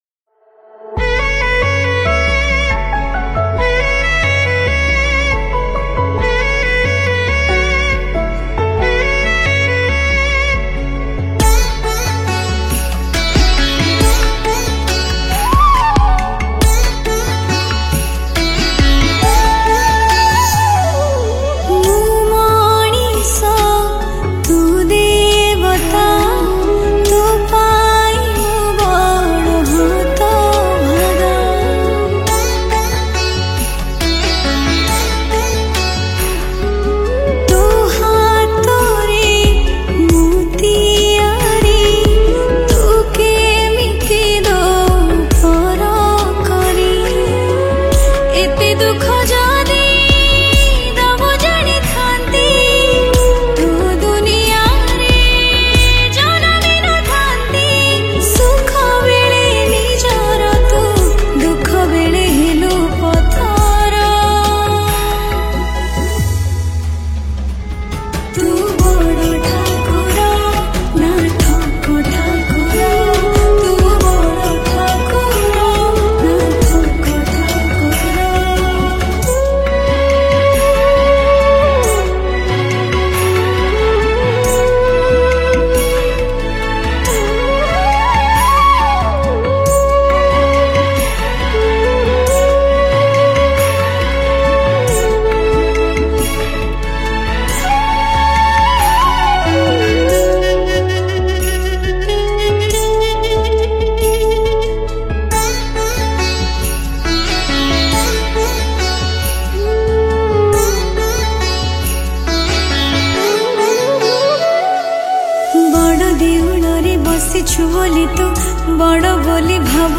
Odia Bhajan Song 2024